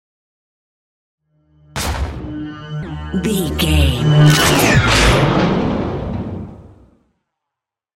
Double hit with whoosh large sci fi
Sound Effects
Atonal
dark
futuristic
intense
woosh to hit